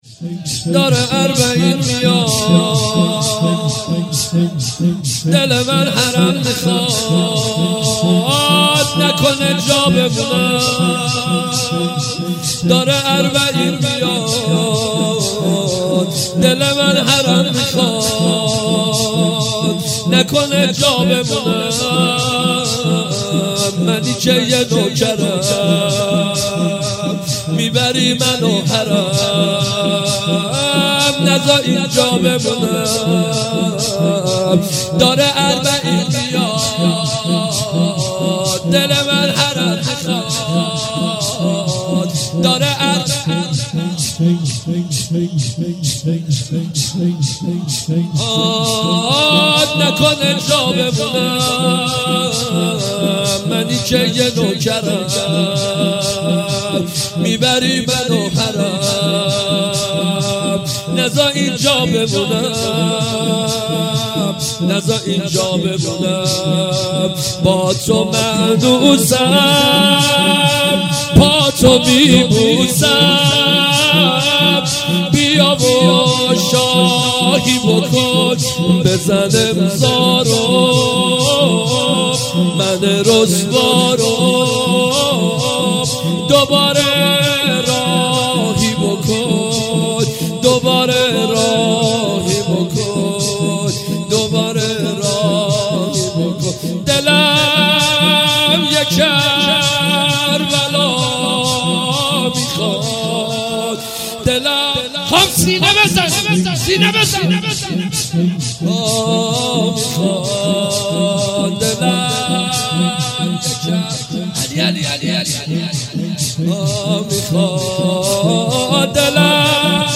شور اربعین